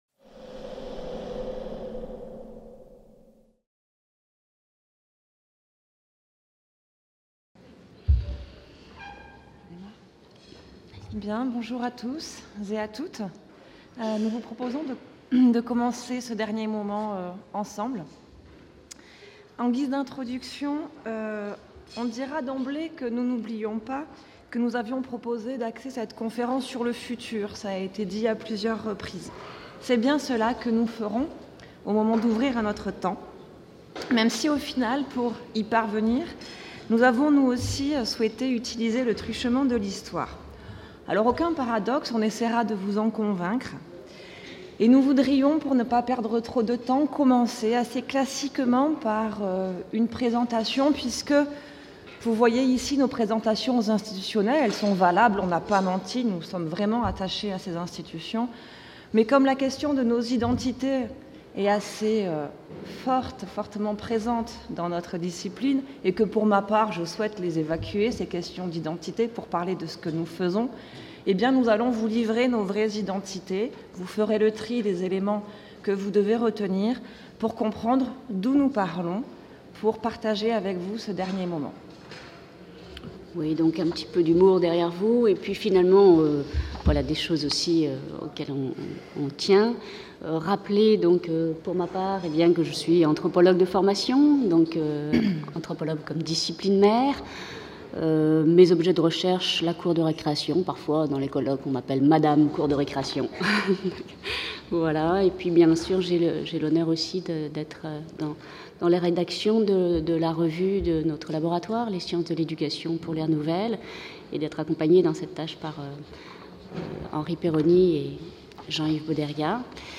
50 ans de sciences de l'éducation - 06 : Conférence de clôture | Canal U